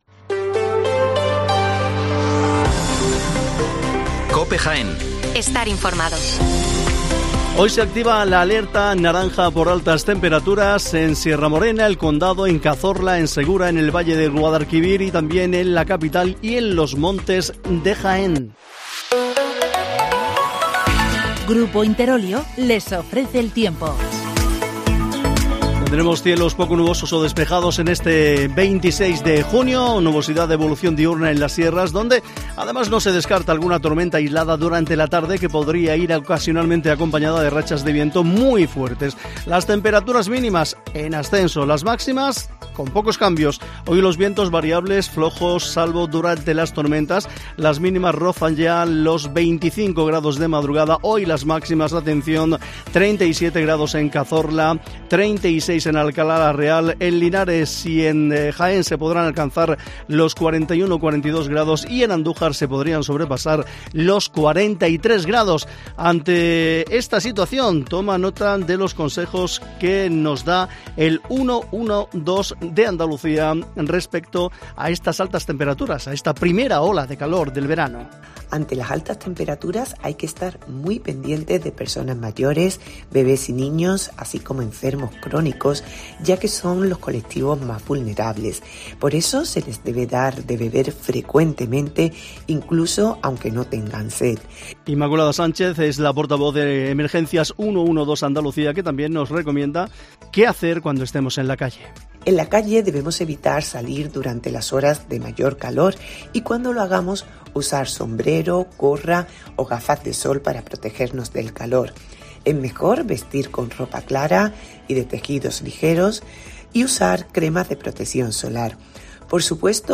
Las noticias matinales en Herrera en COPE 8:24 horas